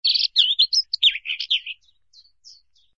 SZ_DG_bird_02.ogg